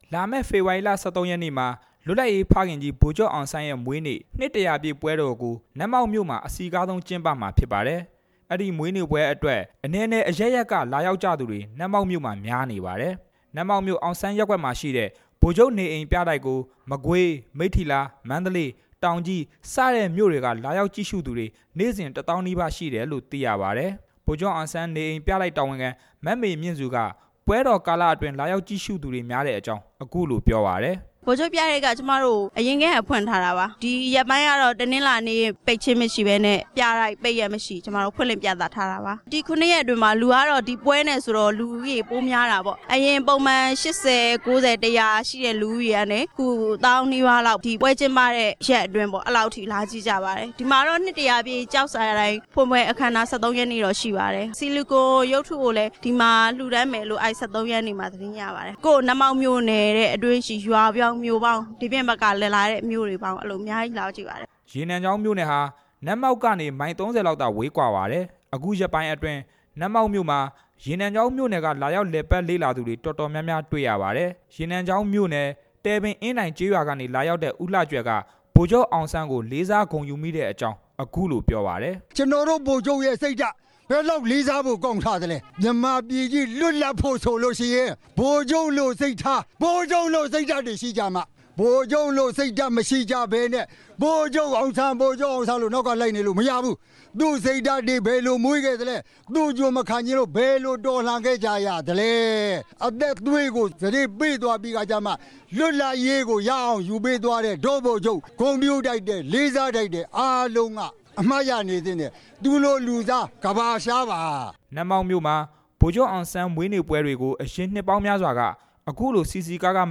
နတ်မောက် ဗိုလ်ချုပ် ရာပြည့် မွေးနေ့အကြောင်း တင်ပြချက်